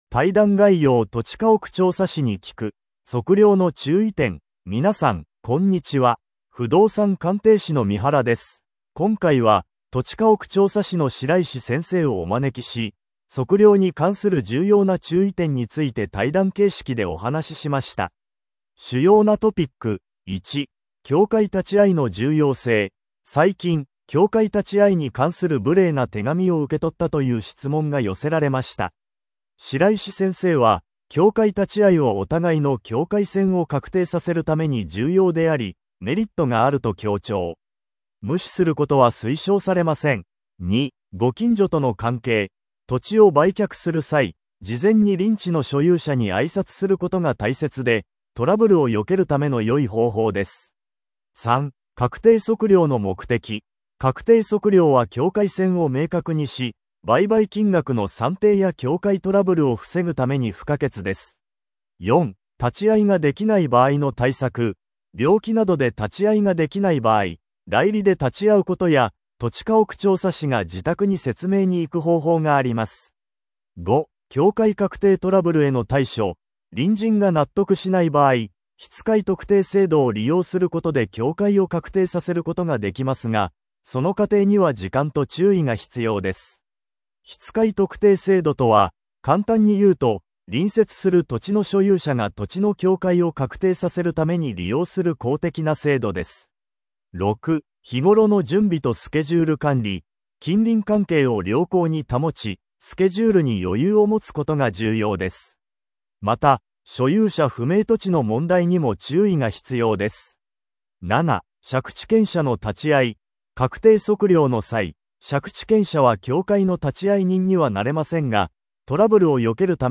対談概要：「土地家屋調査士に聞く。